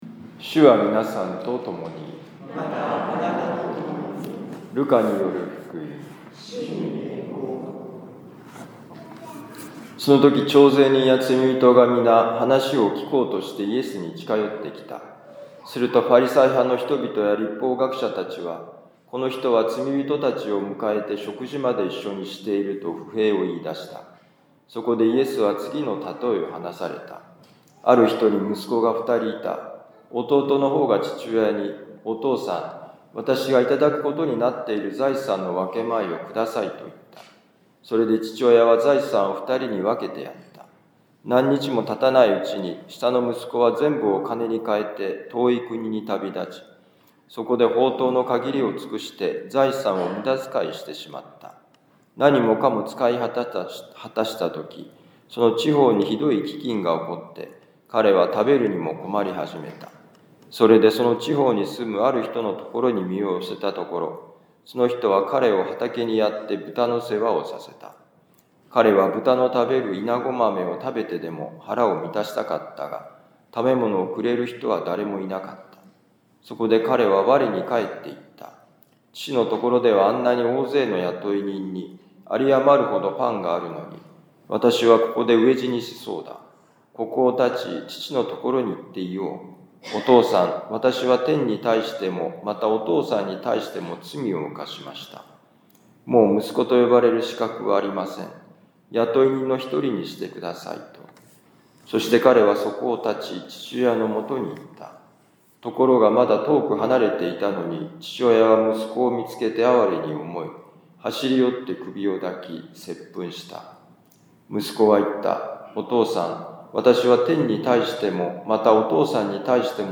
ルカ福音書15章1-3､11-32節「私たちの故郷」2025年3月30日四旬節第４主日ミサ六甲カトリック教会